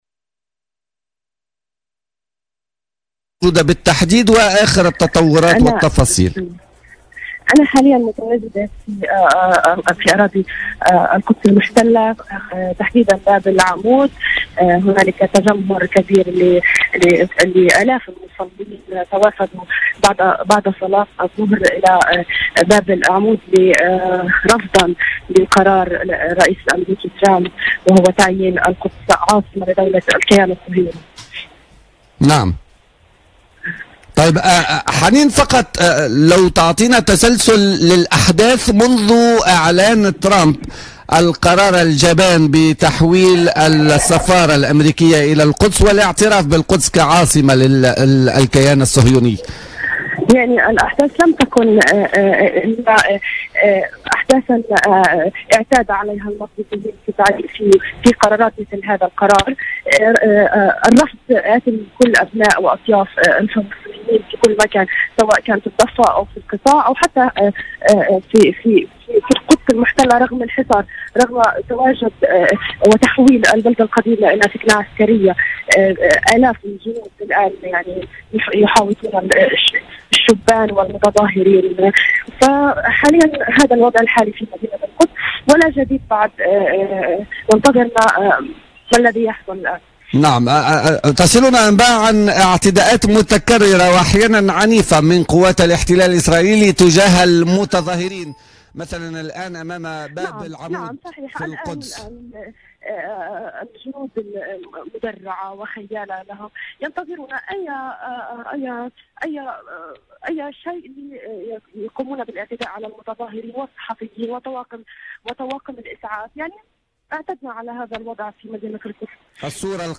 صحفية من القدس : اعتداءات عنيفة من جنود الاحتلال على المحتجين